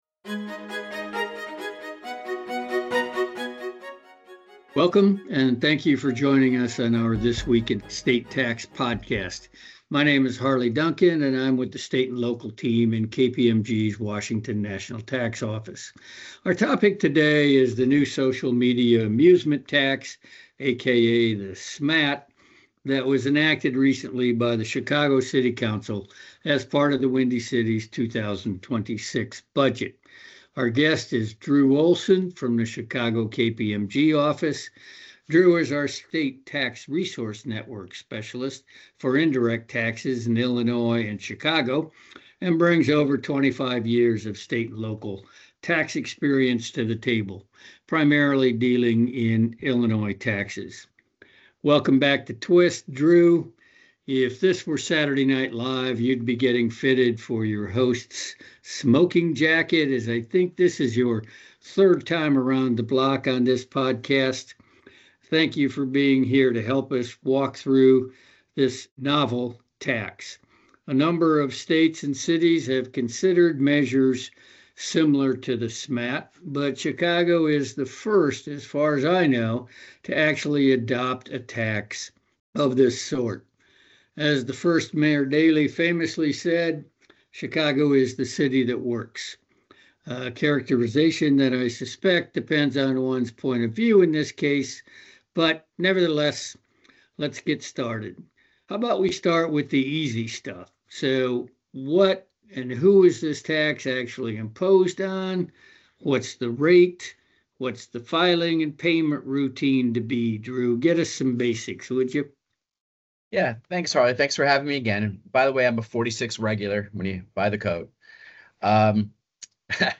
They break down the mechanics of this new tax, discussing the rate, who it applies to, and the monthly filing requirements. The conversation delves into the critical and complex questions businesses are facing, such as what defines a "social media business," how to determine who qualifies as a "Chicago consumer," and the potential for future legal challenges.